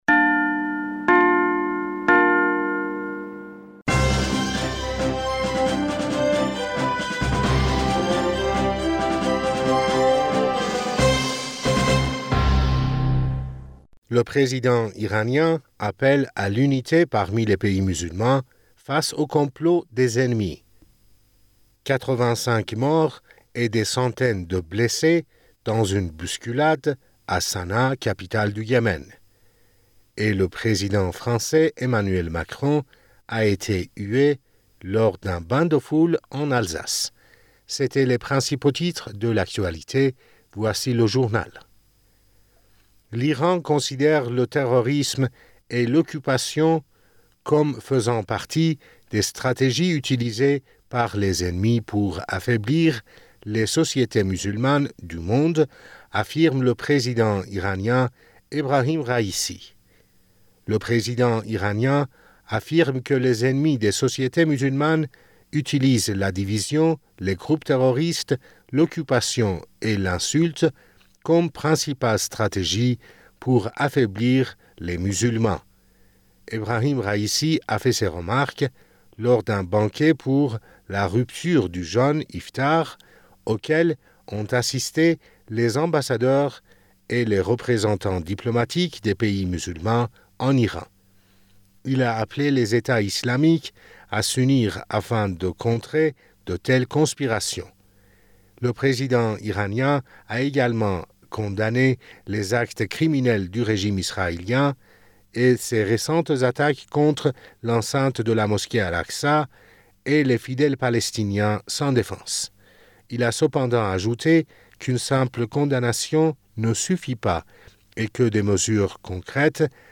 Bulletin d'information du 20 Avril 2023